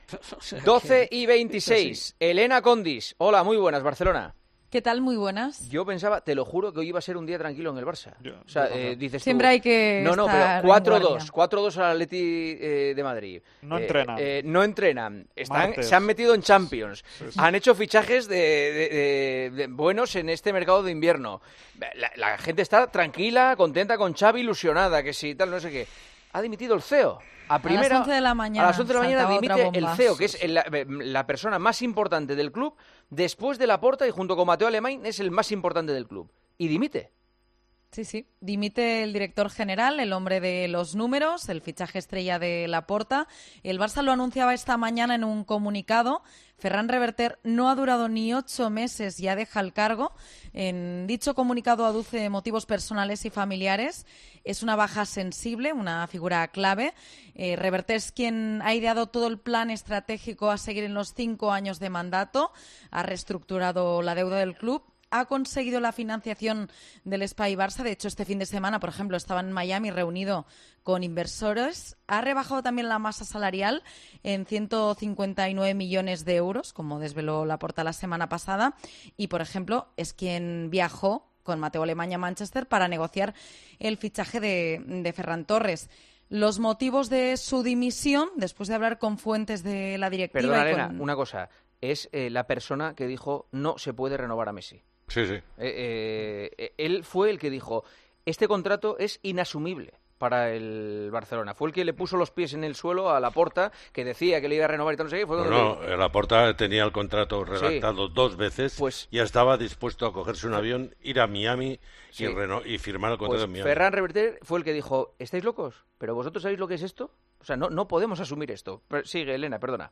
Junto a algunos de los comentaristas de El Partidazo de COPE